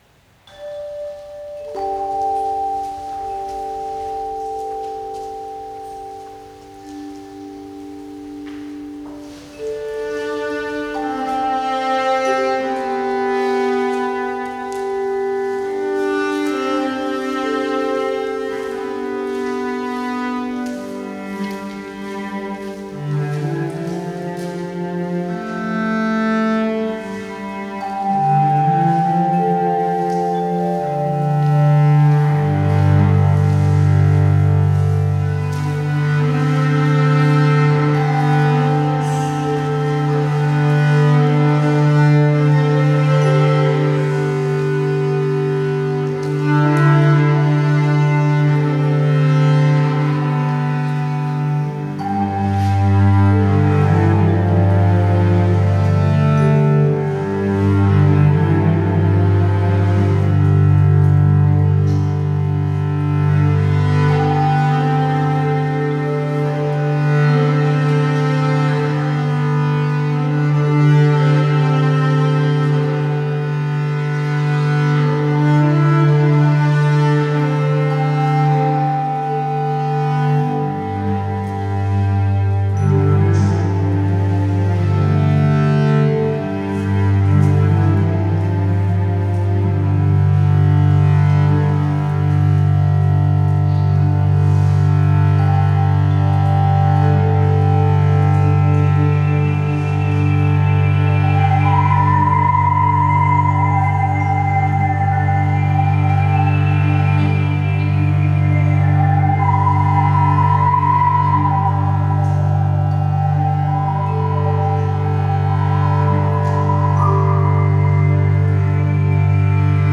manikay
choirchimes
a piece for cello, overtone singing, choirchimes &